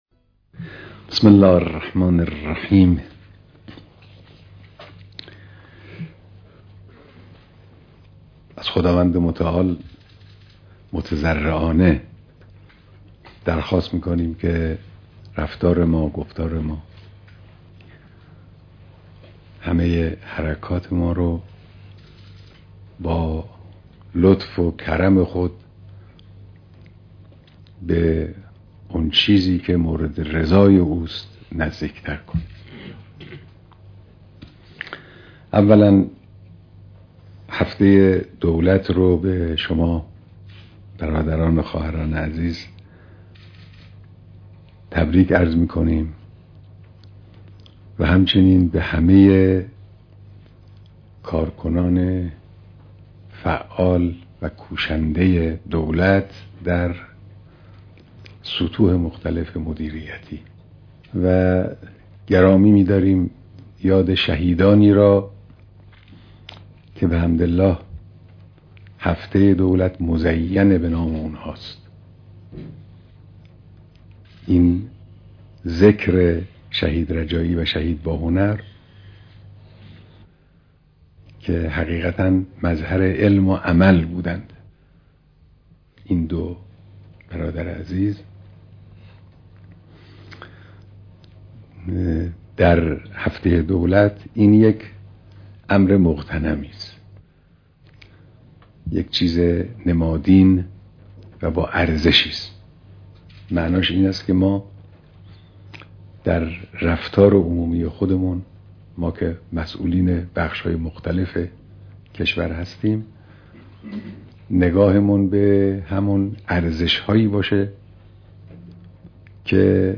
ديدار رئيس جمهور و اعضاي هيأت دولت